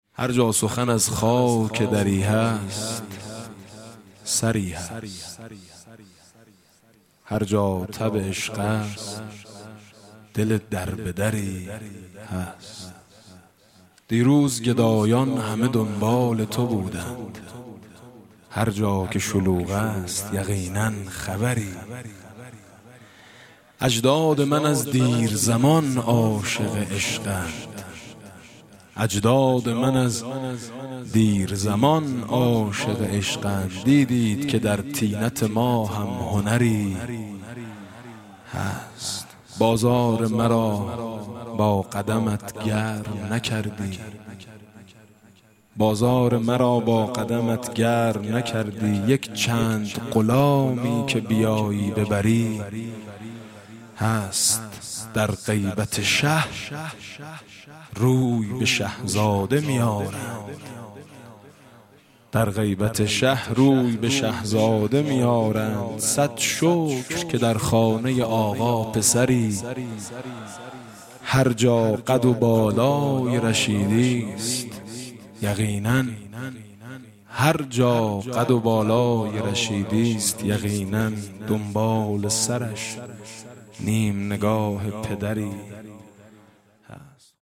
دکلمه: هر جا سخن از خاک دری هست، سری هست
دکلمه: هر جا سخن از خاک دری هست، سری هست خطیب: حاج میثم مطیعی مدت زمان: 00:01:27